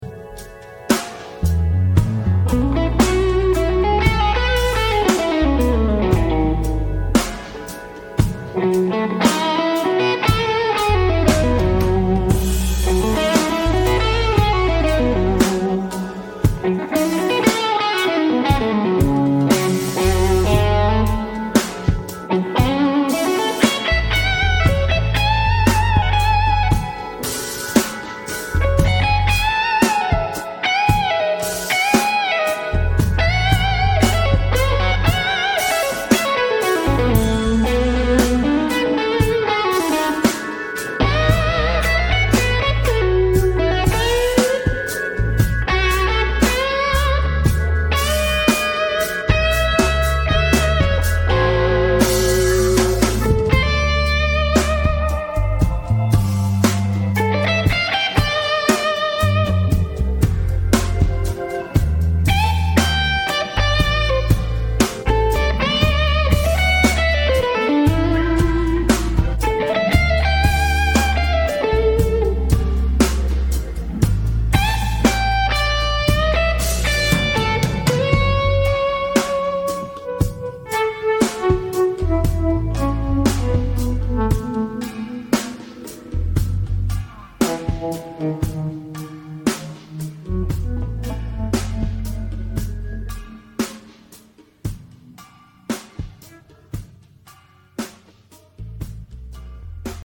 (4) 6L6 NOS GE Power tubes (3) NOS GE 12AX7 Preamp tubes.
Sonically nothing comes close with this much attention to detail and focus for studio quality superiority.